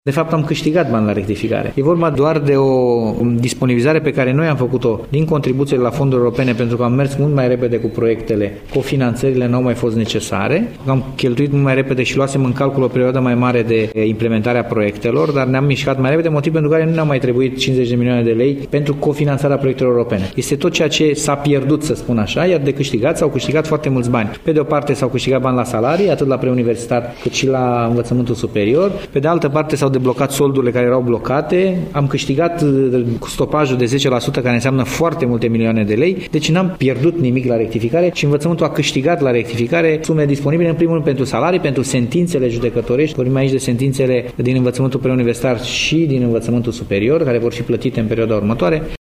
Prezent la ceremonia deschiderii noului an de învățământ la Universitatea din Petroșani, ministrul delegat pentru Învățământ Superior, Cercetare Ştiințifică și Dezvoltare Tehnologică, Mihnea Costoiu, a declarat că învățământul nu pierde fonduri la rectificarea de buget, ci dimpotrivă, are de câștigat.